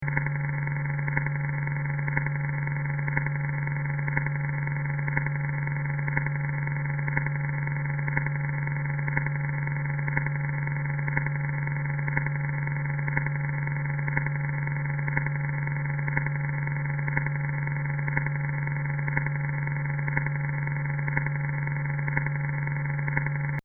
Audio musical
sound art